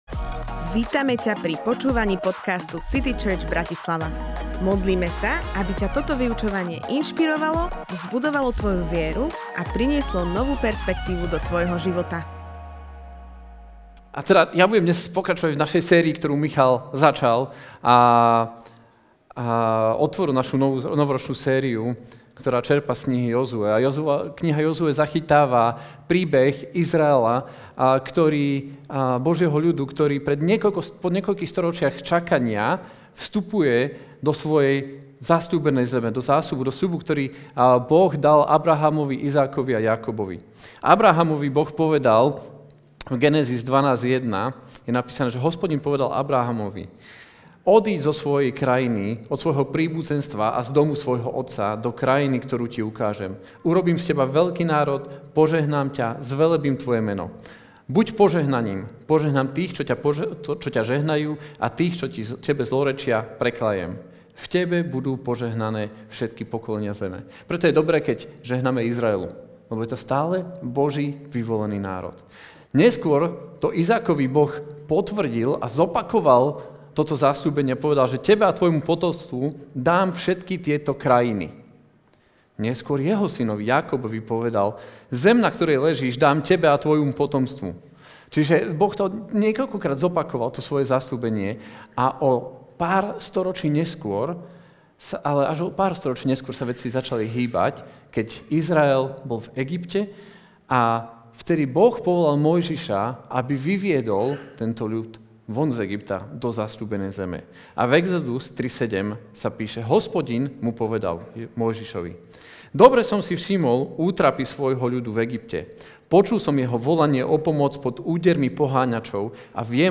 Kázeň týždňa Zo série kázní